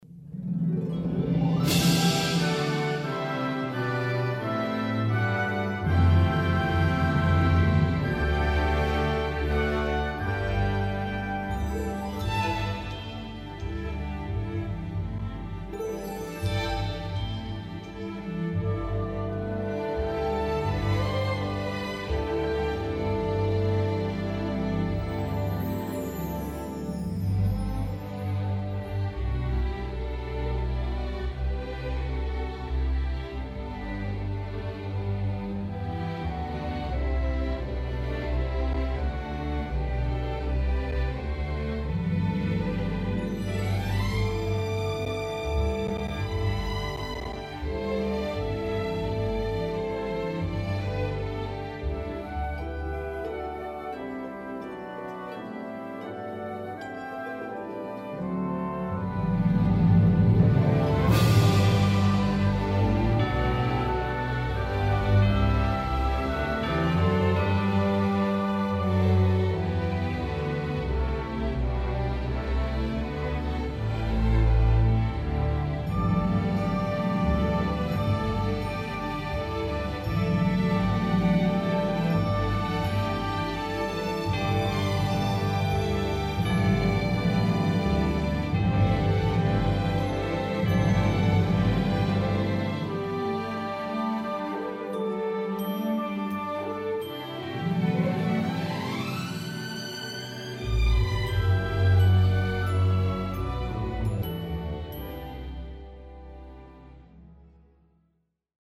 Medium Key without BV